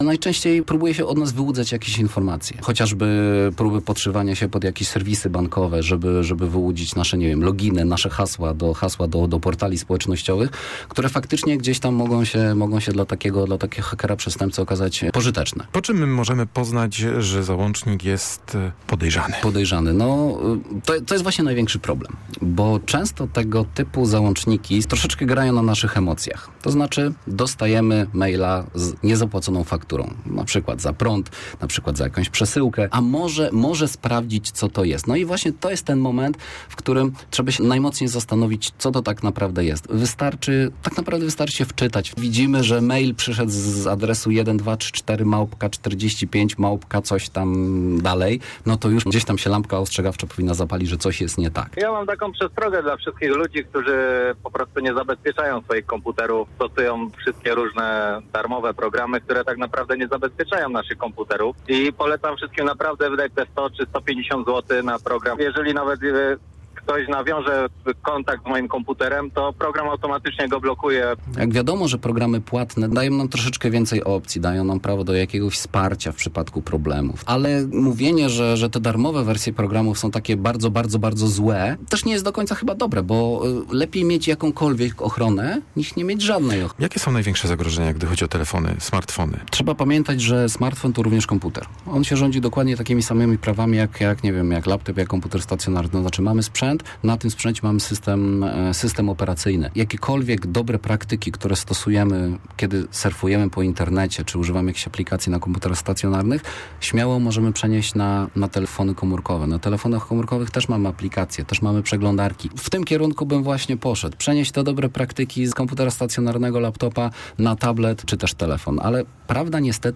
ynnt059ojo1dt4w_skrot-dyskusji-antenowej.mp3